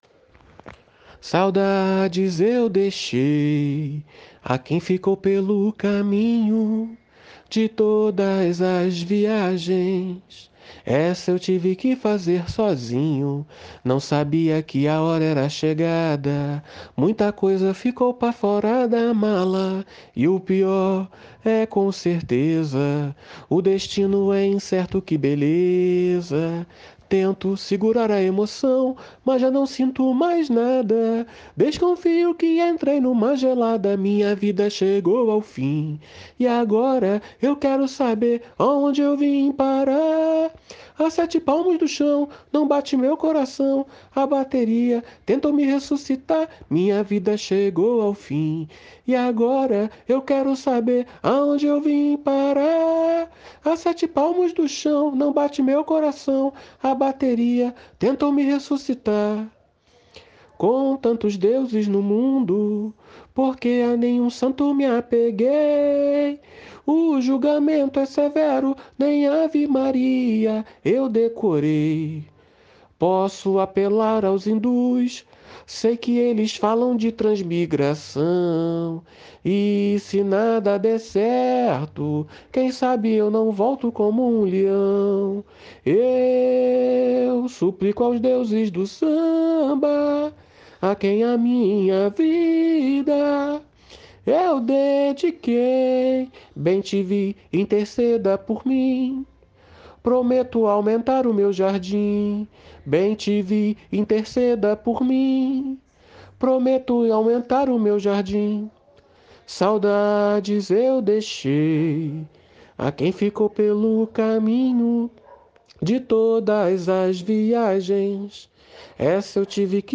Samba  05